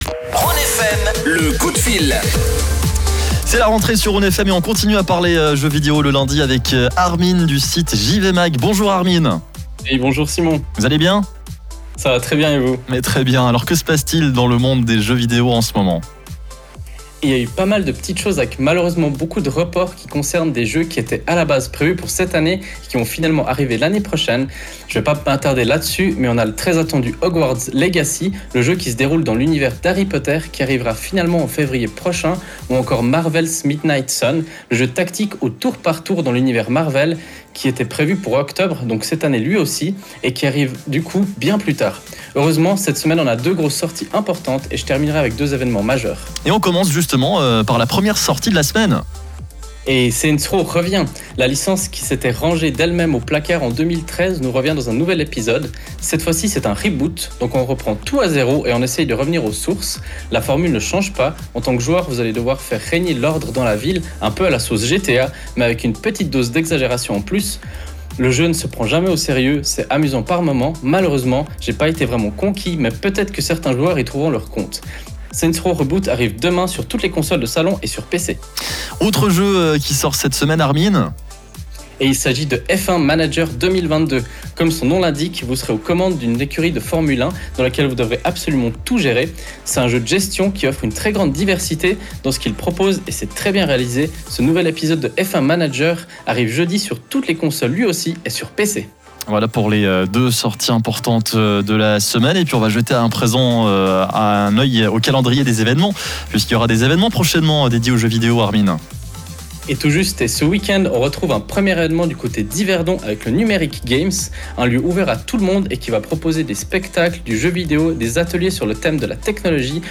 Aujourd’hui, on se tourne vers la sortie de Saints Row Reboot, F1 Manager 22 et des événements Gamescom ainsi que Numerik Games. Le live est à réécouter juste en dessus.
Les-sorties-jeux-video-de-la-semaine.wav